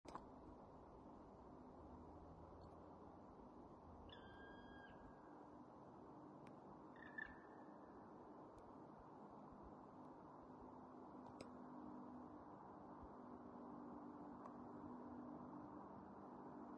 Putni -> Dzeņi ->
Melnā dzilna, Dryocopus martius
StatussDzirdēta balss, saucieni